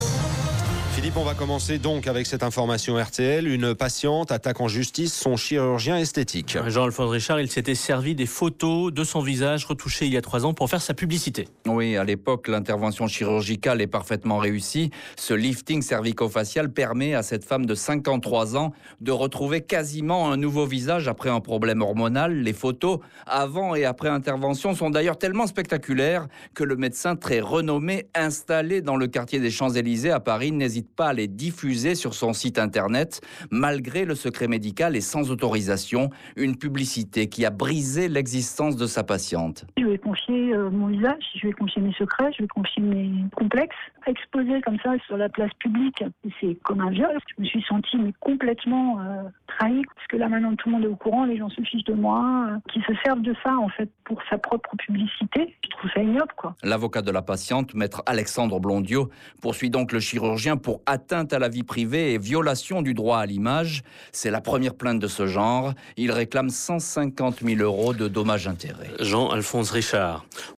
chronique
Interviews et Reportages